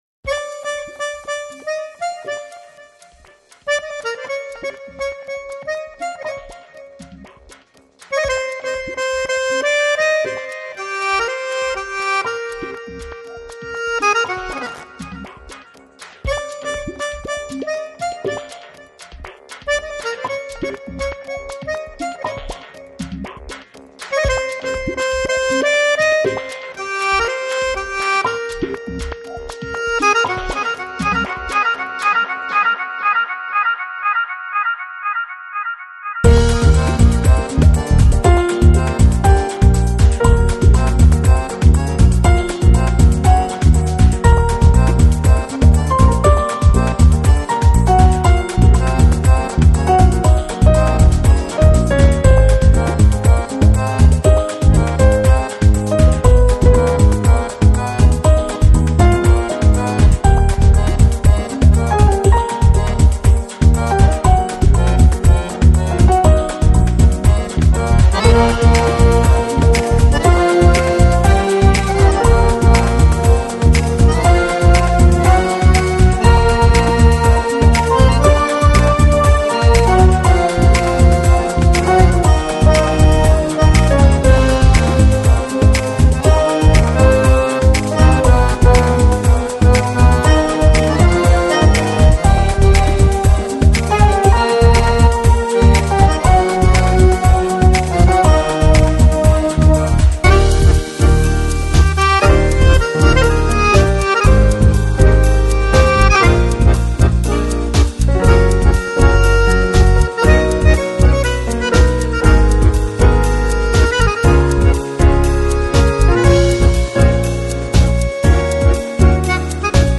Downtempo, Lounge, Chillout Год издания